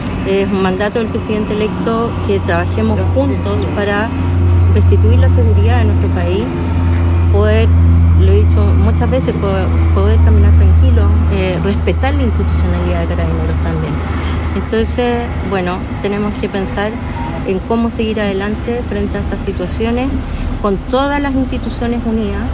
A su vez, la ministra de Seguridad, Trinidad Steinert, dijo que su arribo es porque como institución es querellante, ratificando en su oportunidad que la indagatoria es reservada.